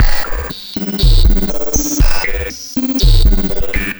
Steam Cleaner Beat.wav